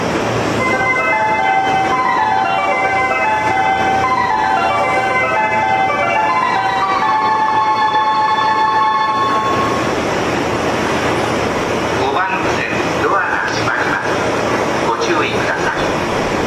発車メロディーは東海道線標準のものです。